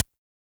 Index of /musicradar/retro-drum-machine-samples/Drums Hits/Raw
RDM_Raw_SY1-Snr03.wav